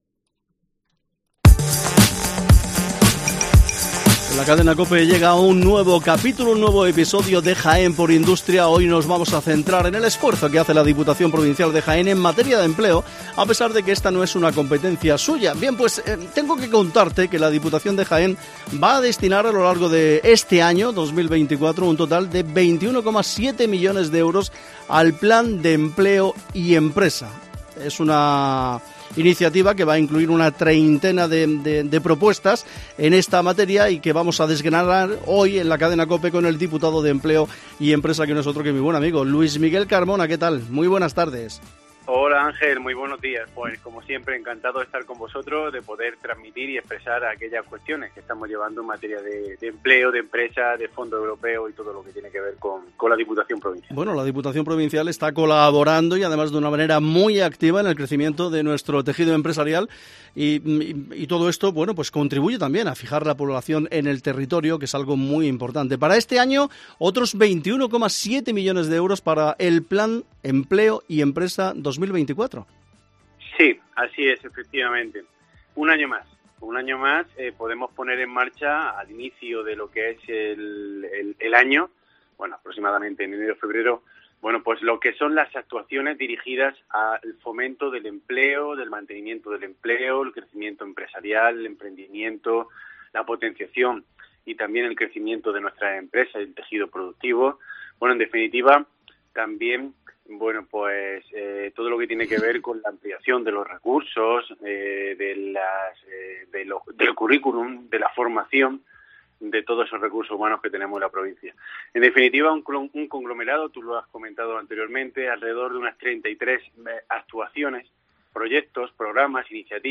Jaén por Industria, hoy con Luis Miguel Carmona, diputado de Empleo y Empresa (15/02/2024)